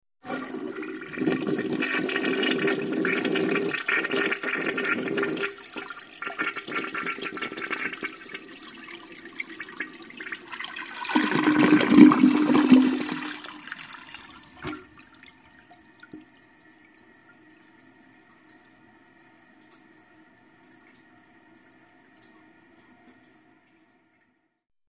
Toilet Flush 4; Typical Flush Routine; Handle Is Pressed, Water Then Flows Abruptly With Water Gurgles Which Fades Into Typical Tank Refill Hiss. Close Perspective. Bathroom.